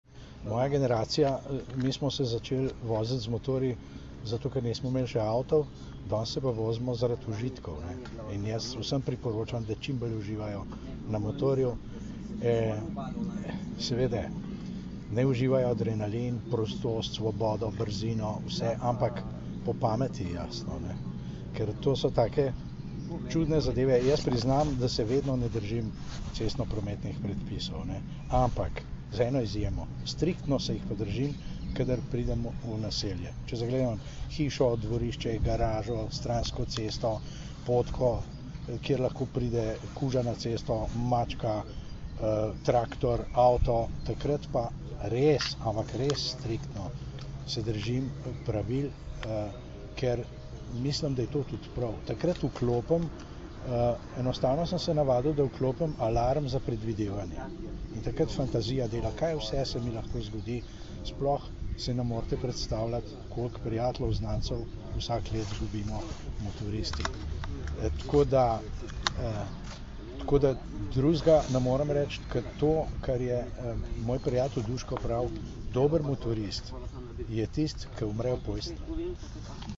Skupaj smo jih zbrali danes, 13. junija 2011, v Policijski akademiji v Tacnu, kjer smo organizirali novinarsko konferenco.